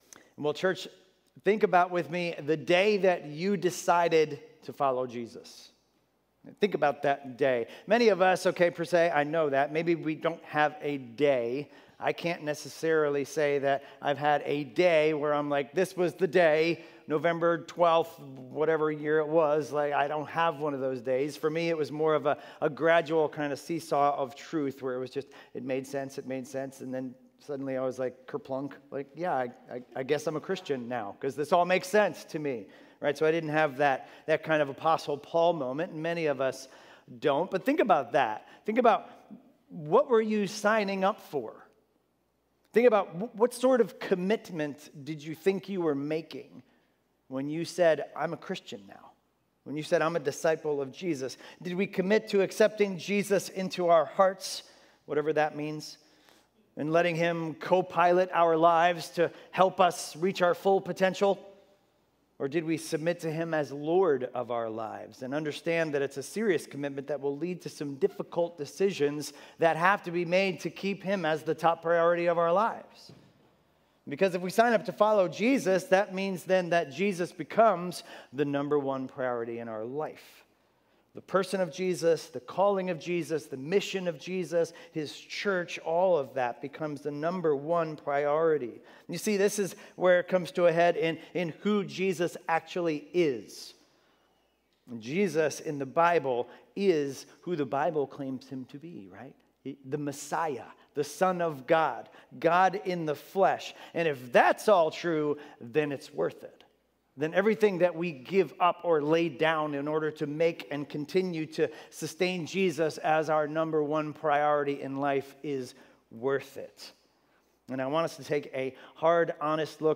Expositional teaching series through the book of Matthew - starting Sunday, Dec 6, 2020